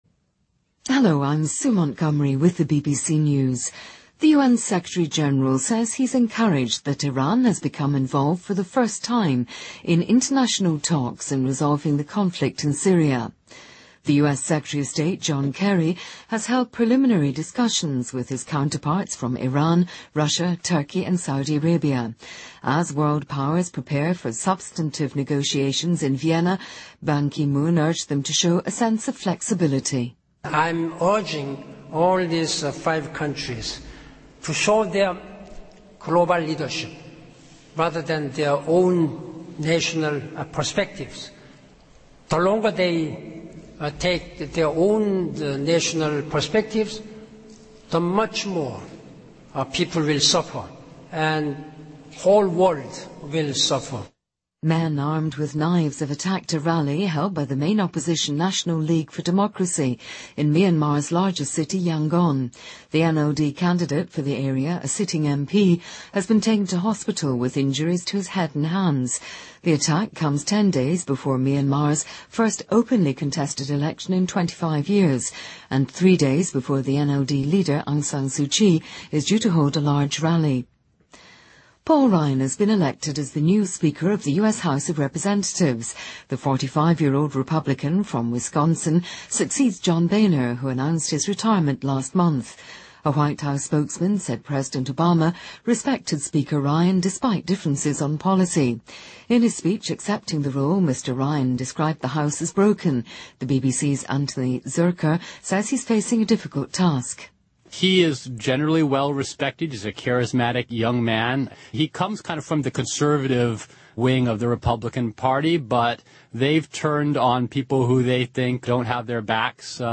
BBC news,中国全面二孩政策将实施